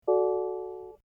SYNC 3 chime (
SYNC_Front_Chime.mp3